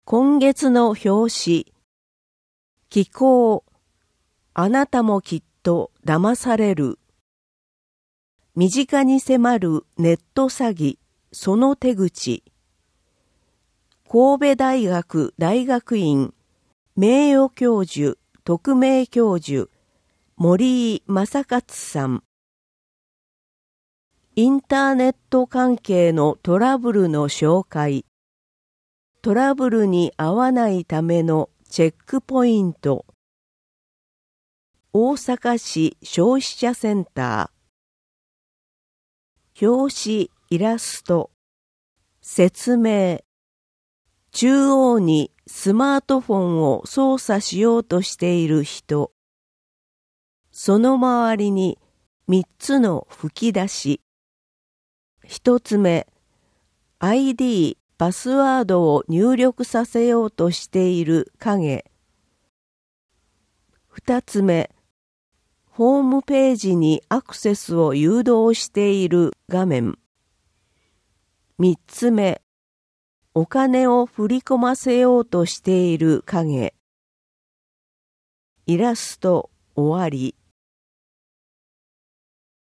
本号につきましては、淀川区で活動されている「音訳ボランティアグループこもれび」の有志の皆様に作成いただきました。